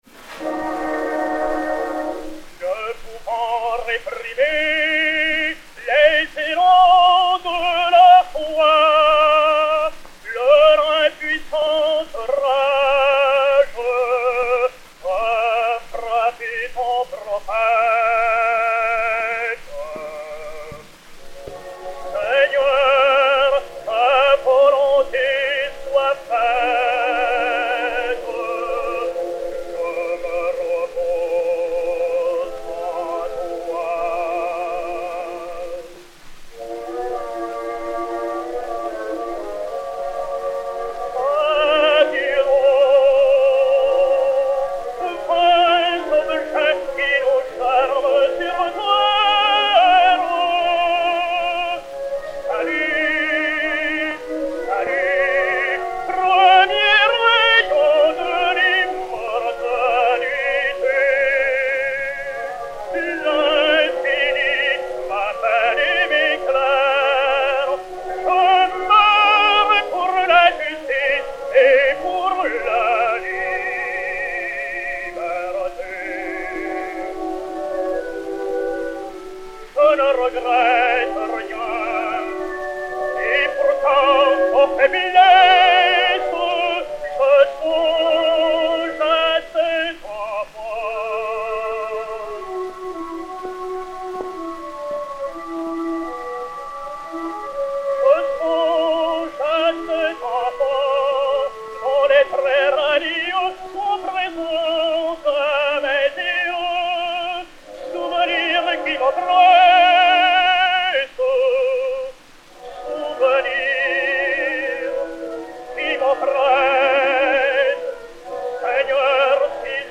Agustarello Affre (Jean) et Orchestre